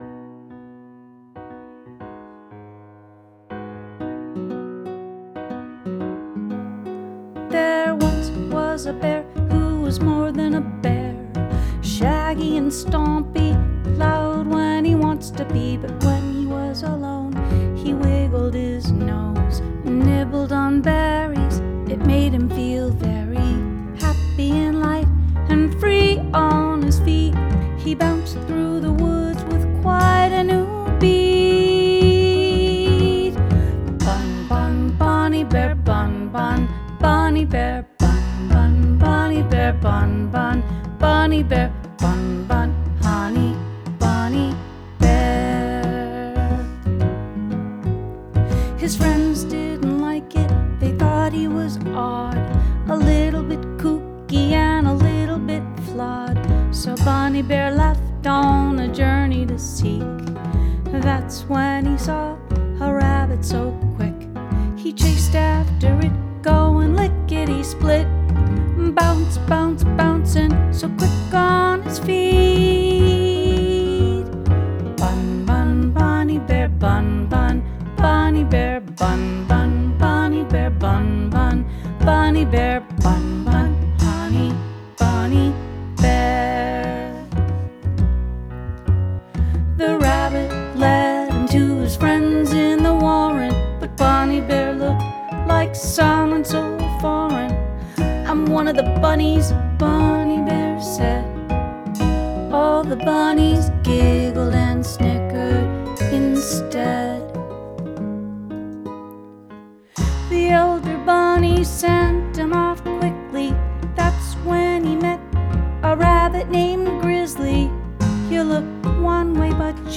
Bunny Bear – book review & tribute song